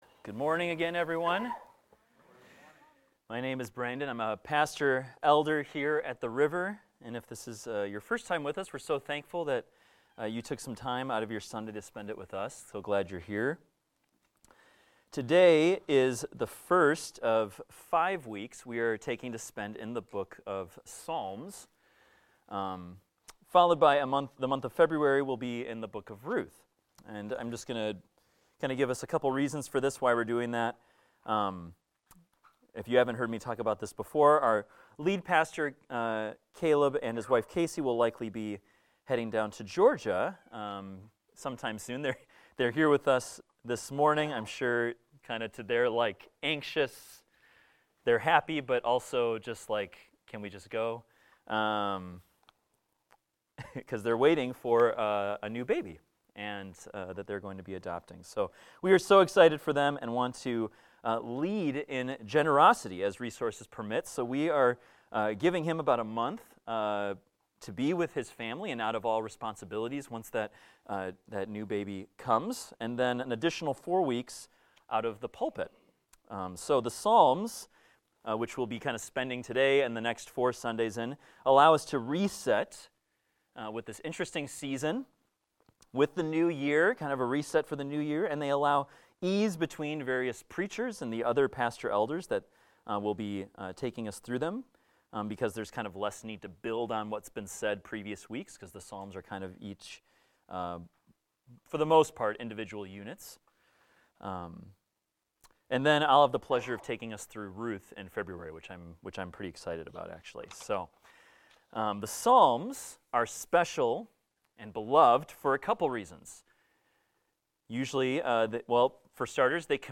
A sermon about Psalm 1 titled "Two Ways to Live"